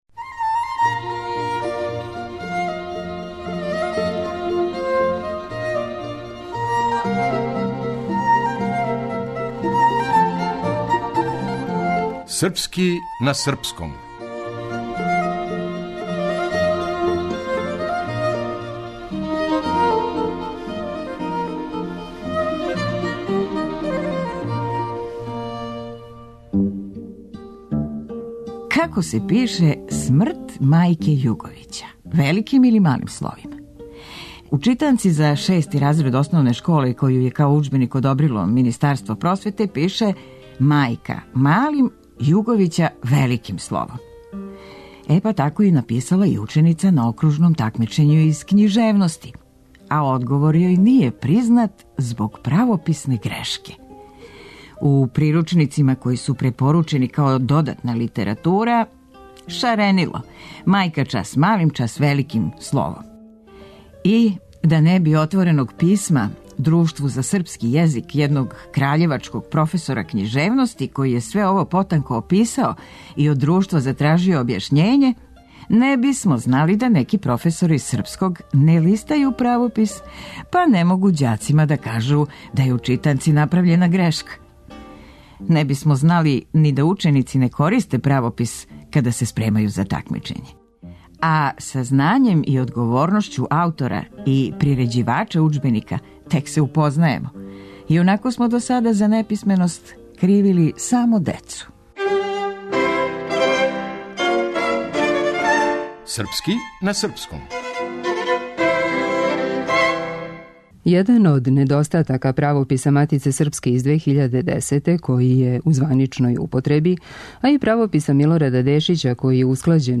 Дрaмскa умeтницa